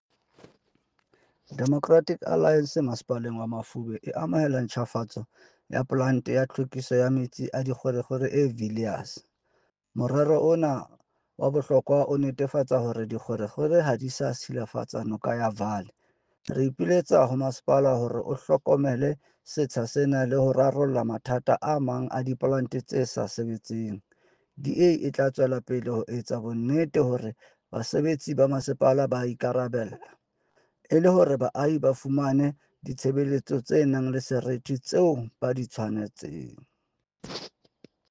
Sesotho soundbites by Cllr Fako Tsotetsi.